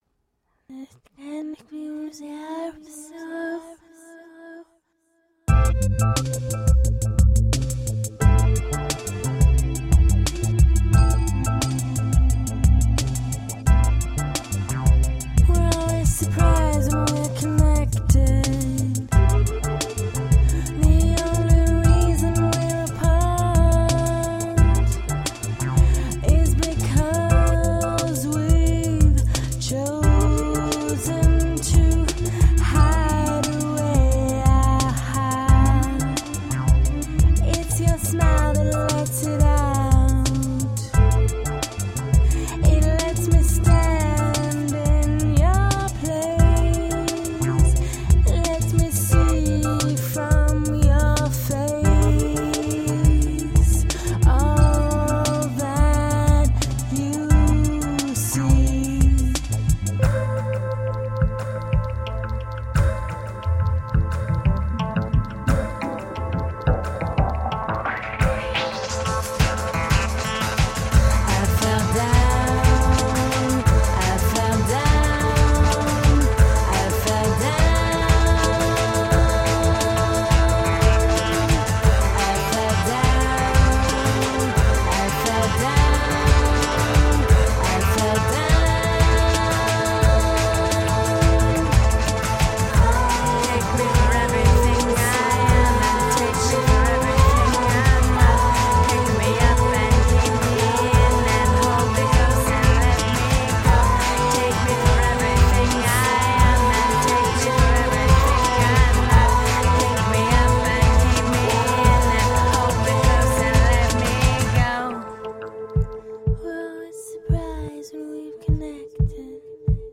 Glamorously femme electropop .
rich, lush vocals
Electro Rock, Pop, Indie Rock
Woman Singing Electro Pop